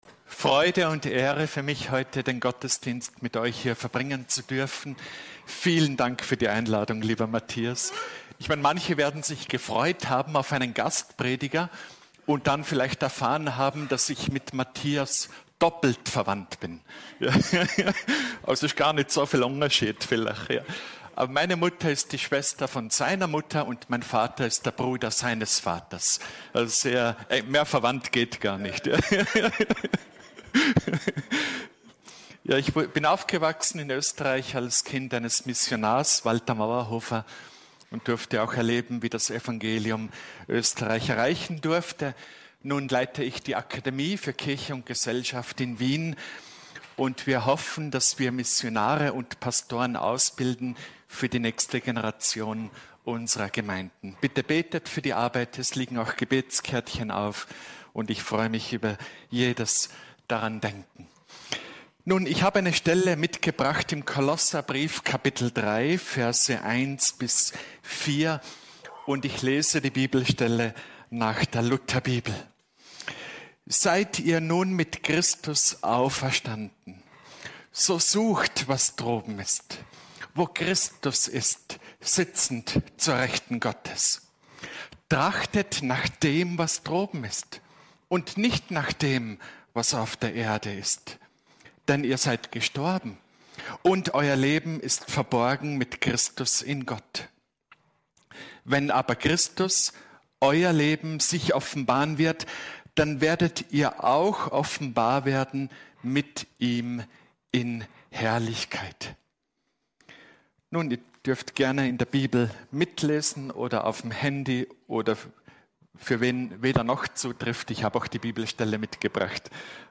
In dieser Predigt aus Kolosser 3,1–4 geht es um das neue Leben, das Christen durch Jesus Christus geschenkt bekommen.